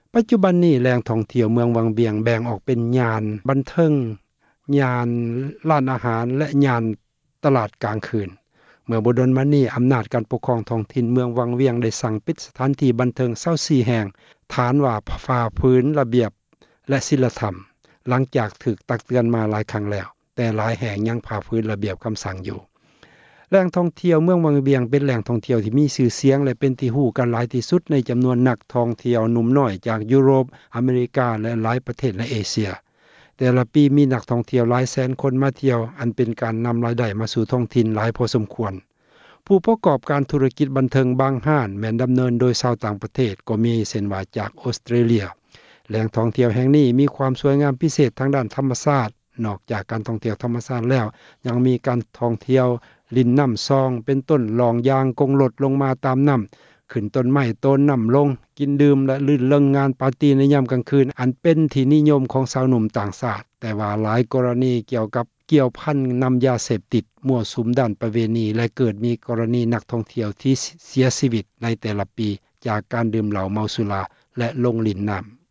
ມື້ນີ້ ເອເຊັຽເສຣີ ໄດ້ໂທຣະສັບ ຖາມຊາວວັງວຽງ ຄົນນຶ່ງ ນາງເວົ້າວ່າ: